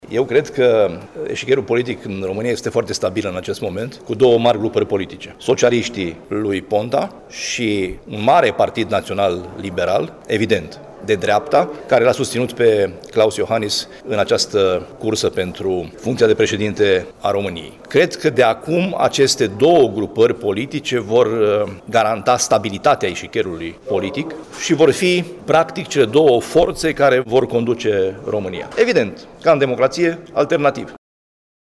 Ascultaţi declaraţia lui Sorin Frunzăverde: